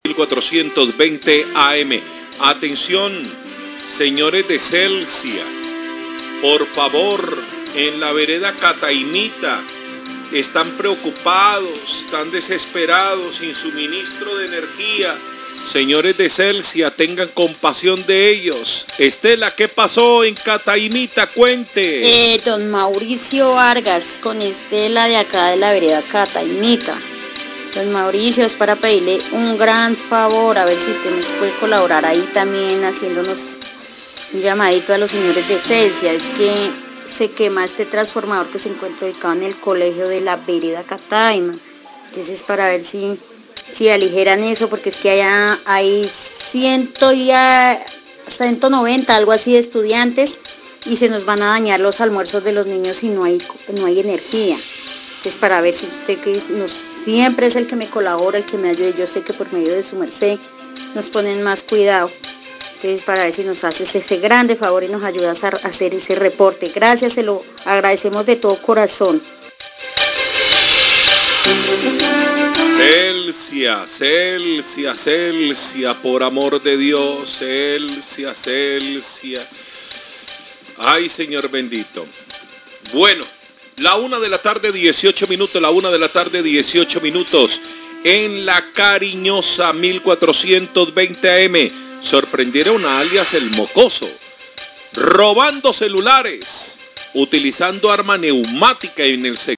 Radio
Usuaria de Celsia hace llamado urgente por quema de un transformador ubicado en la escuela de la vereda Cataimita, la señora dice que la comida de los estudiantes está a punto de dañarse por falta de electricidad para refrigerar los alimentos